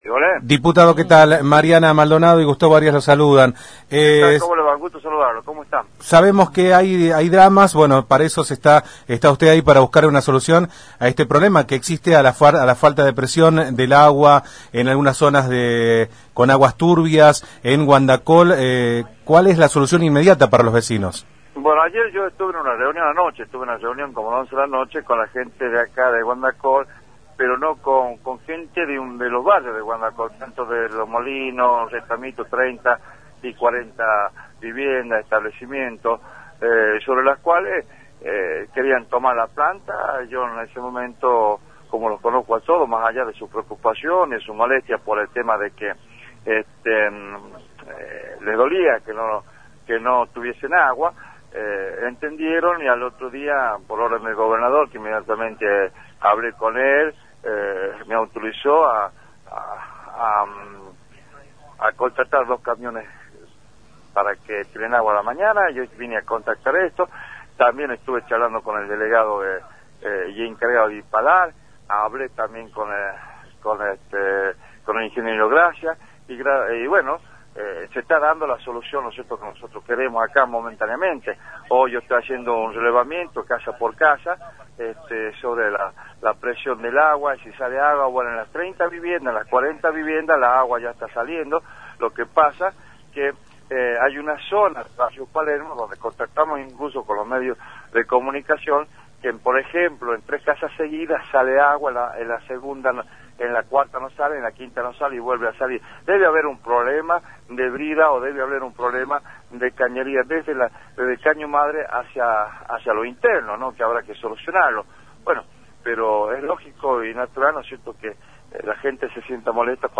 Ángel Páez, diputado por Felipe Varela, por Radio Independiente
c3a1ngel-pc3a1ez-diputado-por-felipe-varela-por-radio-independiente.mp3